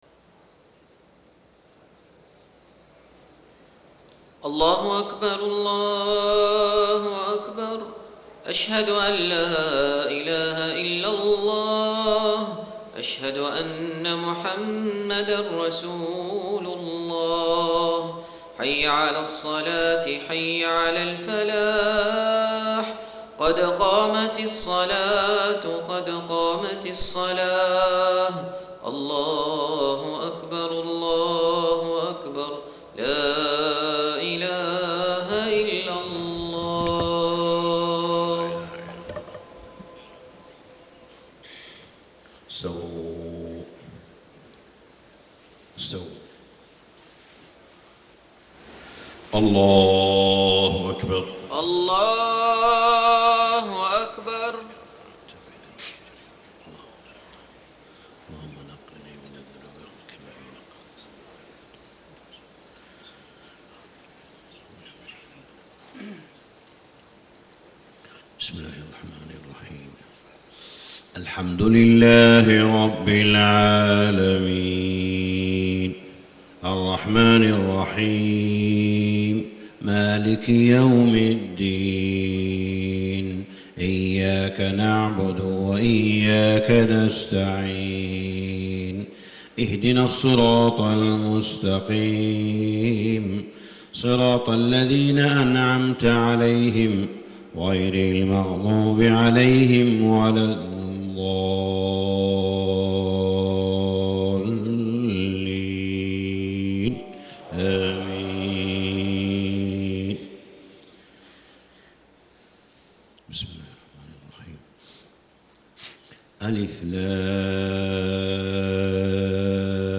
صلاة الفجر 1-5-1435 ما تيسرمن سورة ال عمران > 1435 🕋 > الفروض - تلاوات الحرمين